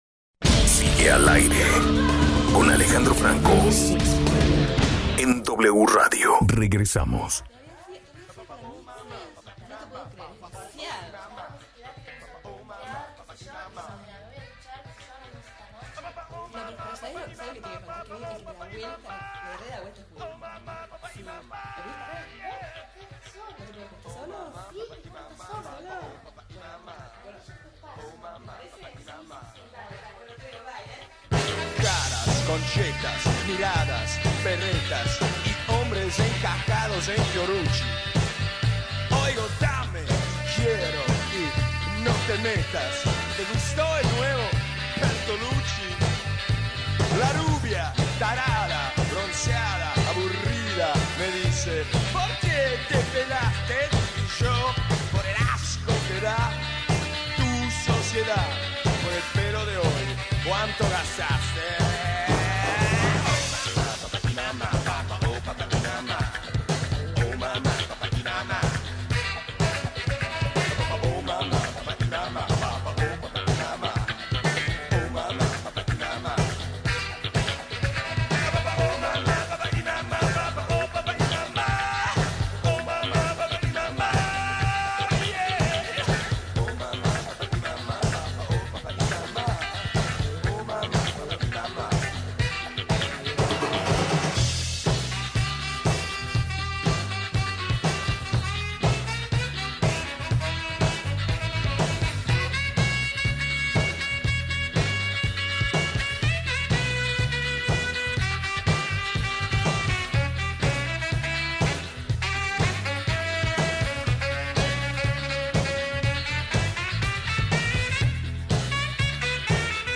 Conversaciones